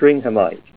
Help on Name Pronunciation: Name Pronunciation: Stringhamite + Pronunciation
Say STRINGHAMITE Help on Synonym: Synonym: ICSD 30926   PDF 29-318